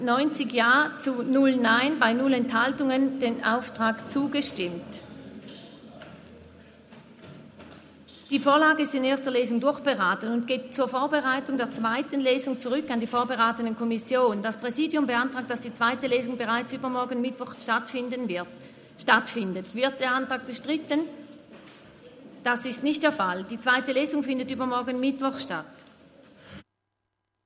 Session des Kantonsrates vom 12. bis 14. Juni 2023, Sommersession